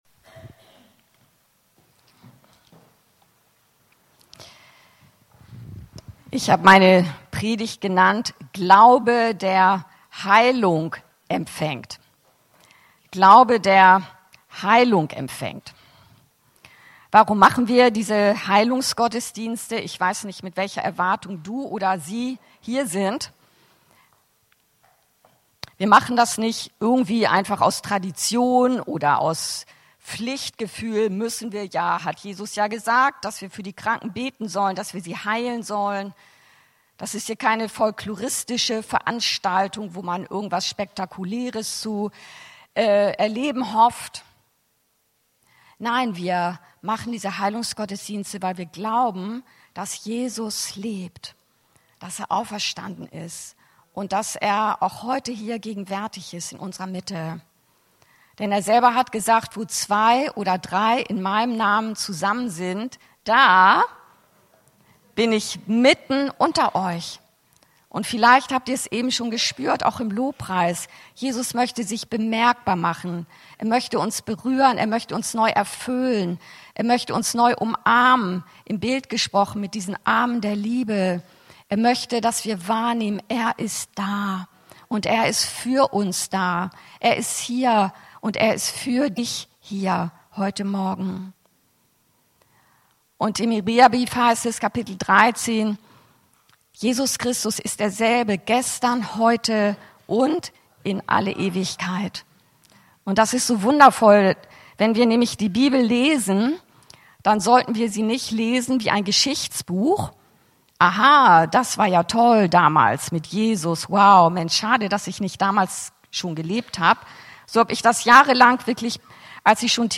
Predigten und Lehre aus der Anskar-Kirche Hamburg-Mitte